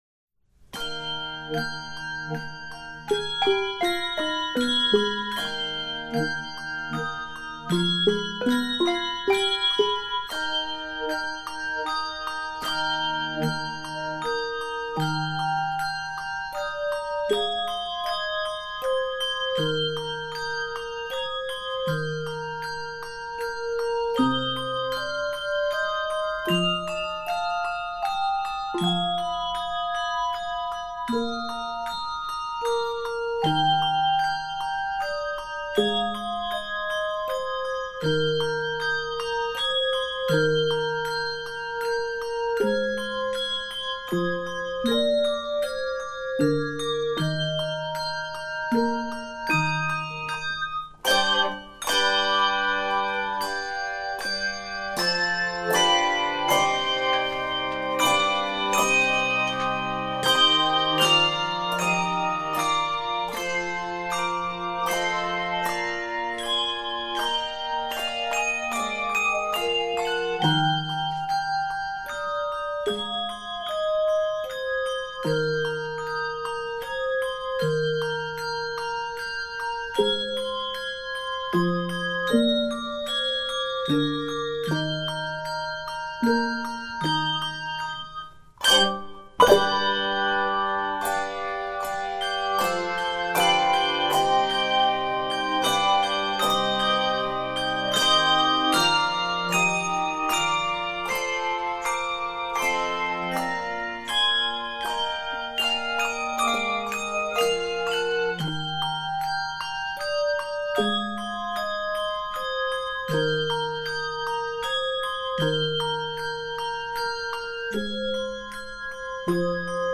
expressive
Keys of Eb Major.